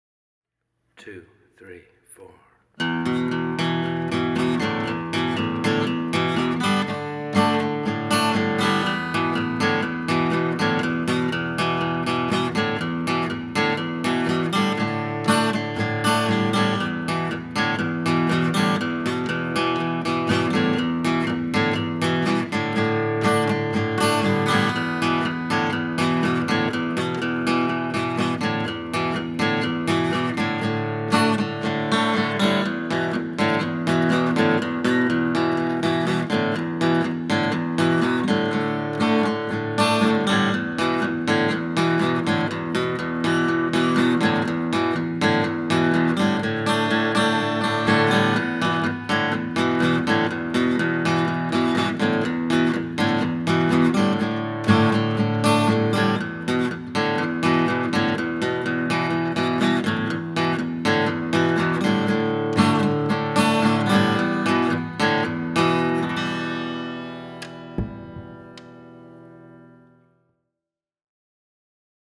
Here’s some very rough recordings from our first rehearsal.
We Are a Circle – fast rhythmic version
Cantos20-RhythmGuitar-Circle01.m4a